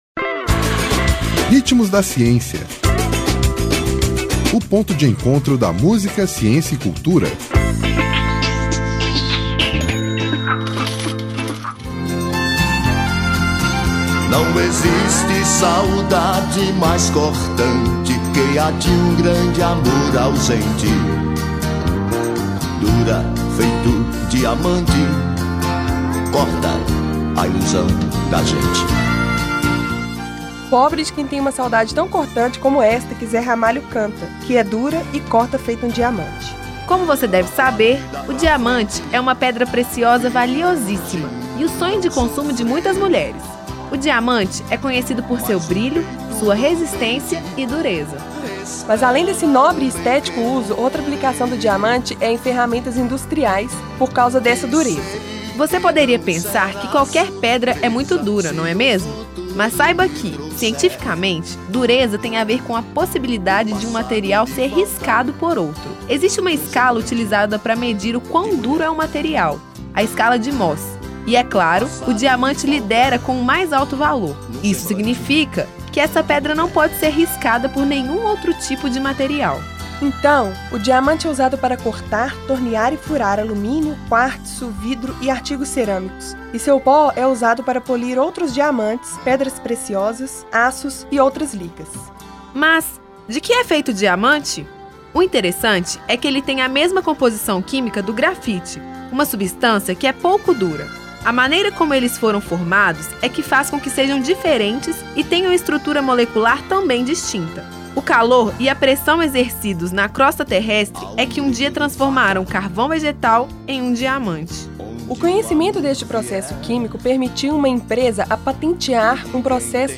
Nome da música: Ansiedade
Intérprete: Zé Ramalho